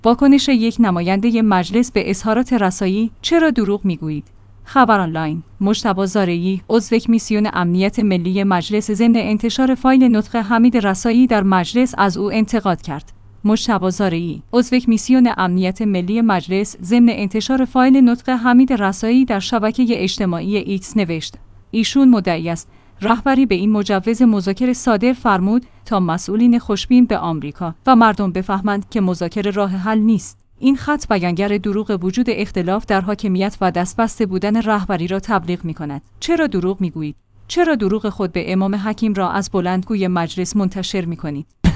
خبرآنلاین/ مجتبی زارعی، عضوکمیسیون امنیت ملی مجلس ضمن انتشار فایل نطق حمید رسایی در مجلس از او انتقاد کرد.